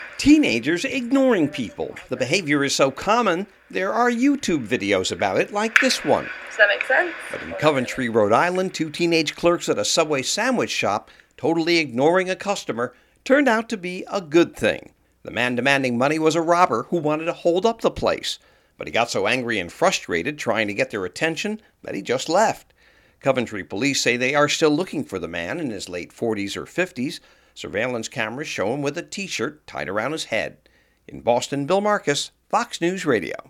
(BOSTON) JULY 31 – POLICE IN COVENTRY, RHODE ISLAND SAY A MAN AT A SUBWAY SANDWICH SHOP WHO WALKED OUT BECAUSE THE CLERKS IGNORED HIM TURNED OUT TO BE A GOOD THING FOR THAT BUSINESS. FOX NEWS RADIO’S